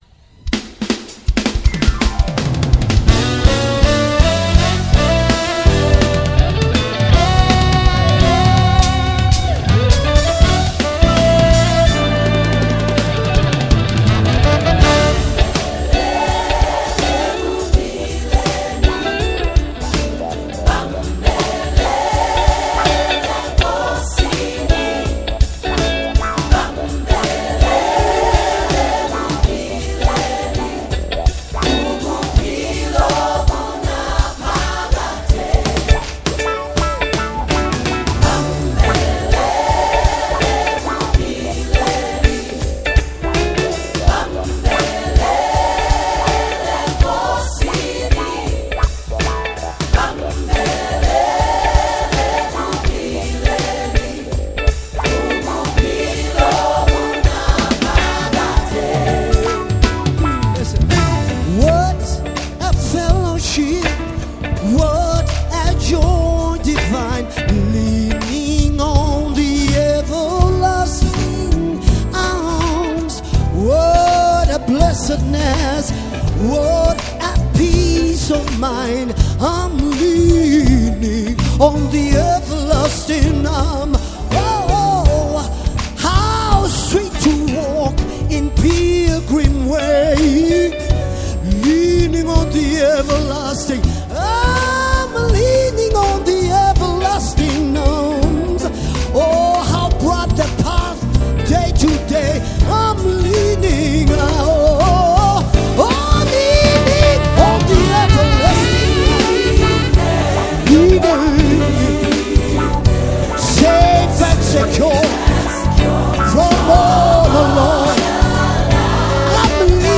soul-stirring collaboration
With its uplifting melody and heartfelt lyrics
harmonious backing vocals